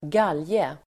Uttal: [²g'al:je]